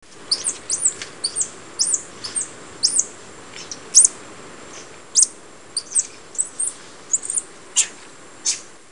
White-fronted Capuchin (Cebus albifrons)